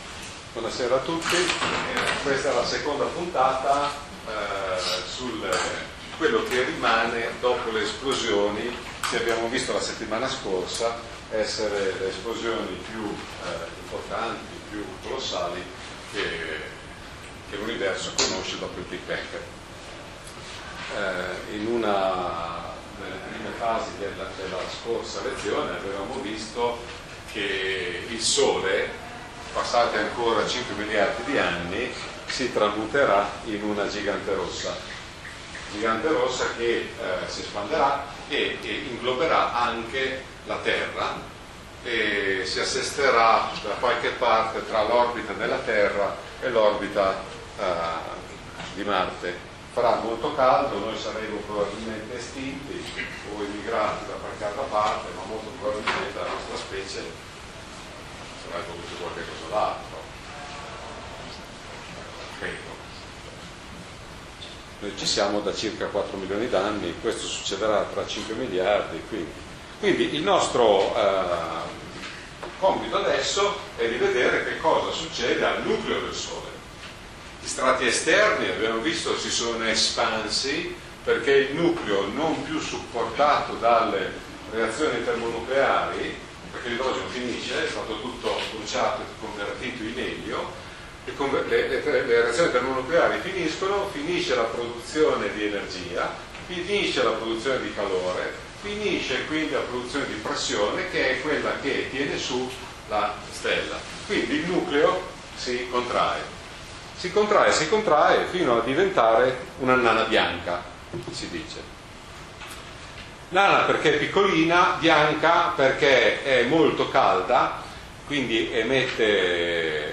L’universo in fiore - corso di astronomia 2011-12 - Home page - INAF-OAB